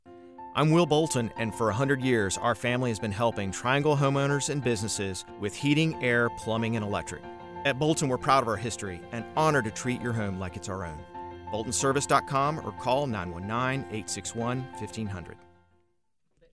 As Heard on Capitol Broadcasting